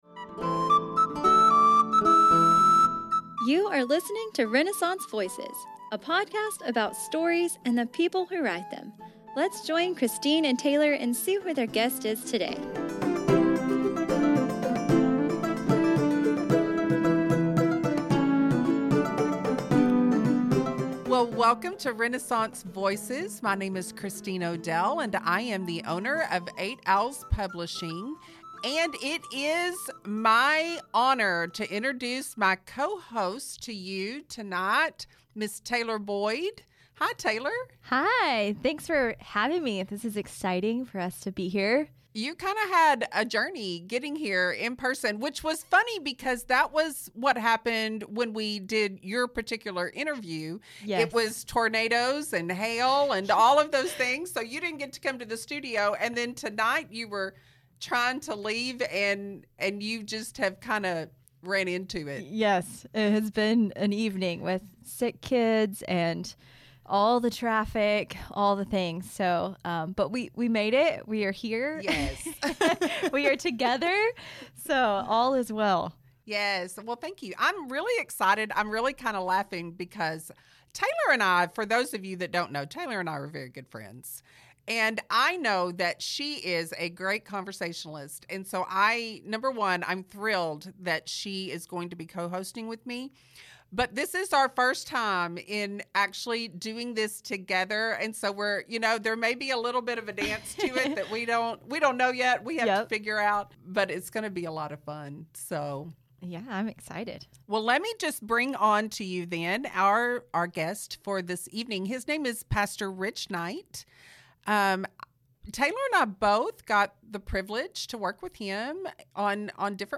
This is an engaging conversation